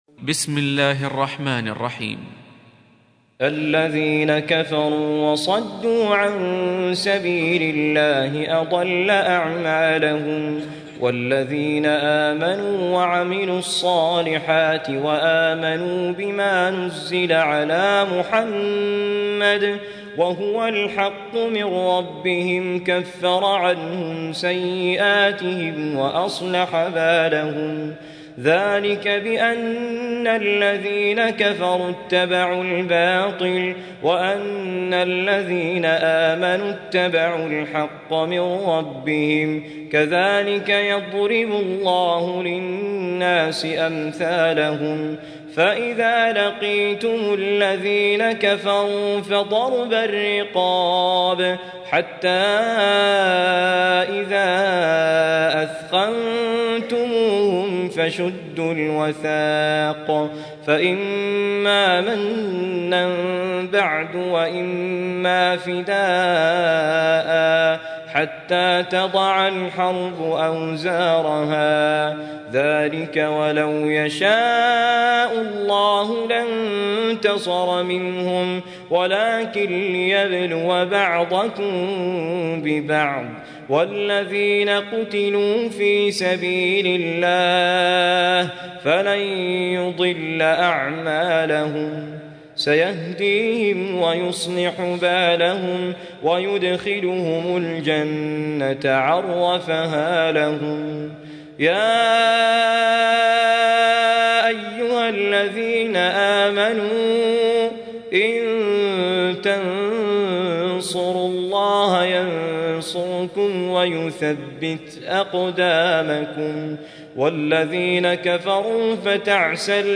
Surah Repeating تكرار السورة Download Surah حمّل السورة Reciting Murattalah Audio for 47. Surah Muhammad or Al-Qit�l سورة محمد N.B *Surah Includes Al-Basmalah Reciters Sequents تتابع التلاوات Reciters Repeats تكرار التلاوات